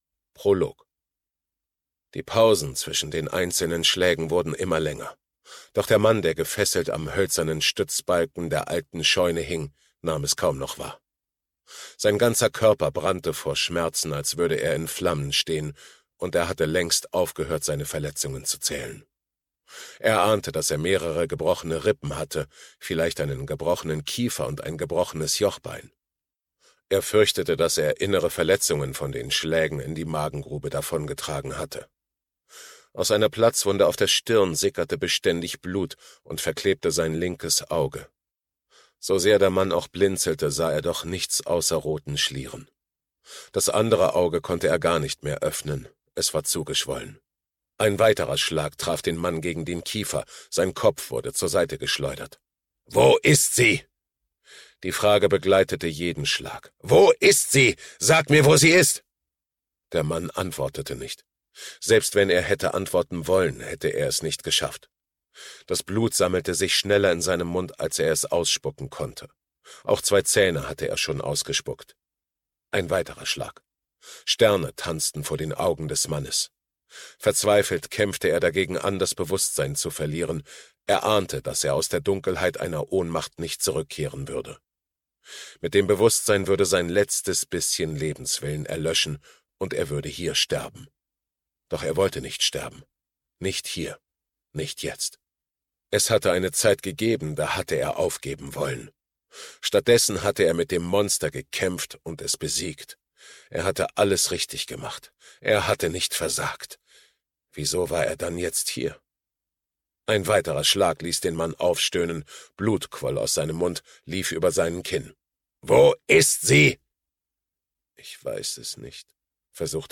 2022 | Ungekürzte Lesung